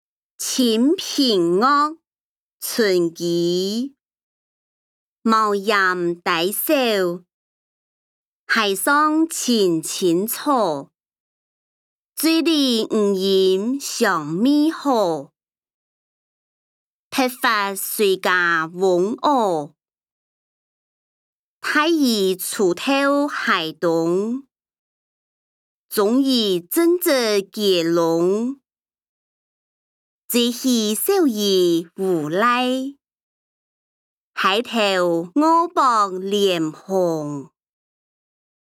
詞、曲-清平樂：村居 音檔(四縣腔)